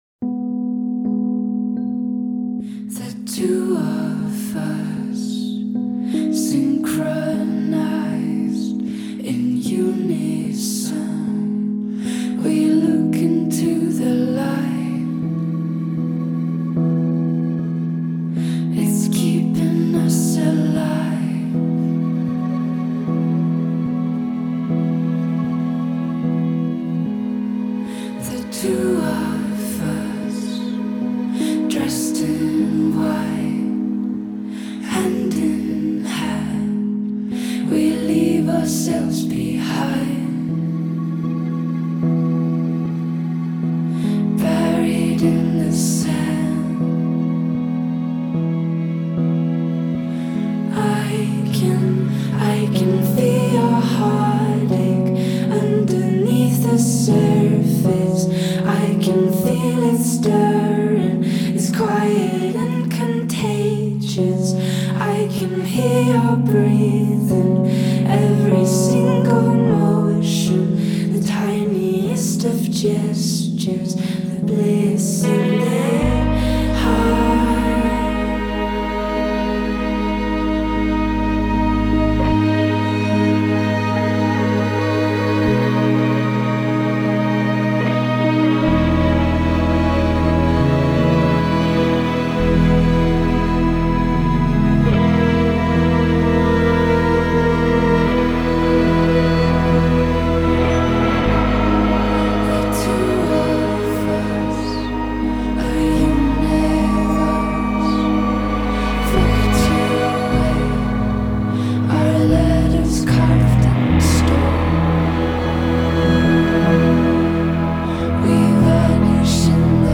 Genre: Electronic, Indie Pop